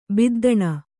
♪ biddaṇa